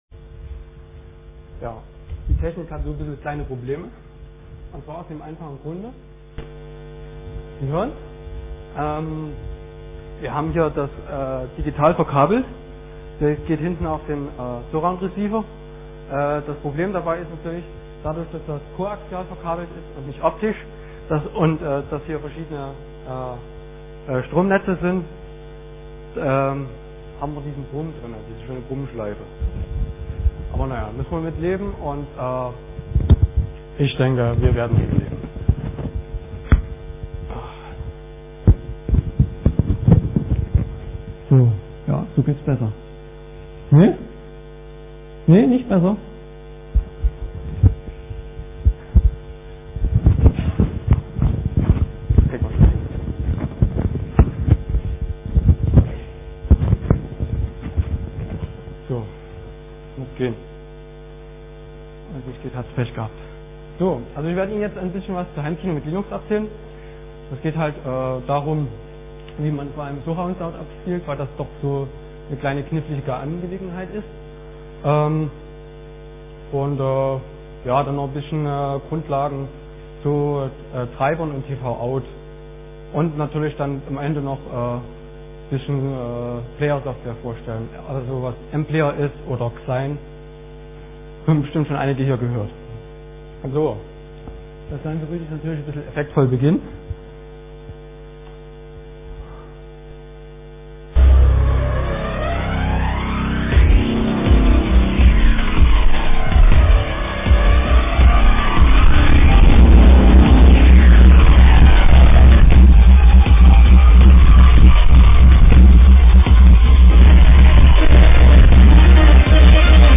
Samstag, 14:00 Uhr im Raum V4 - Multimedia
Vortragsmittschnitt